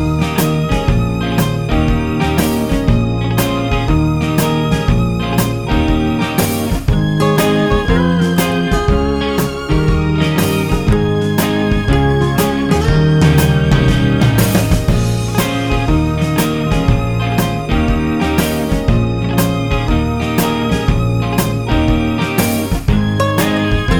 no Backing Vocals Rock 'n' Roll 3:53 Buy £1.50